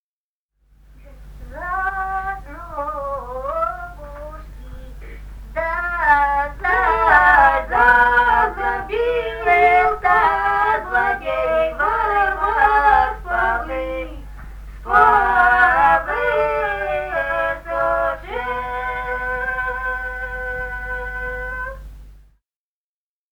Русские народные песни Красноярского края.
(лирическая). с. Тасеево Тасеевского района.